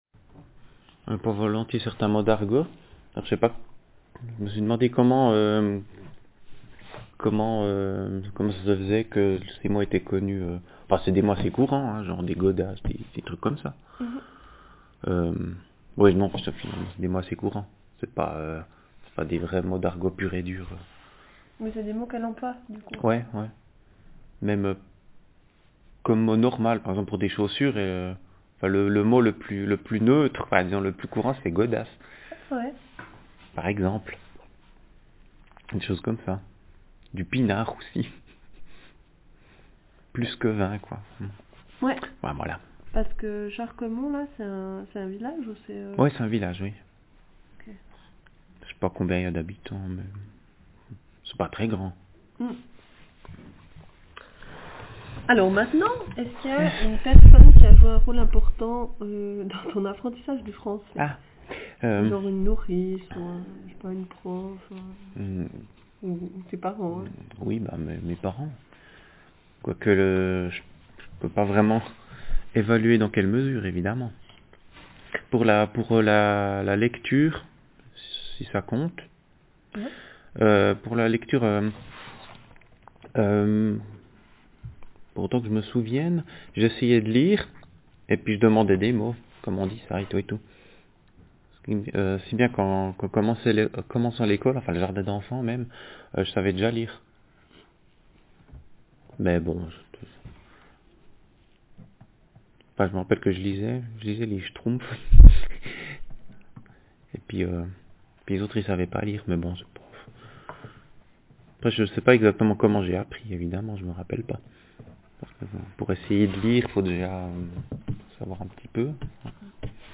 DoReCo - Language French (Swiss)
Speaker sex m Text genre personal narrative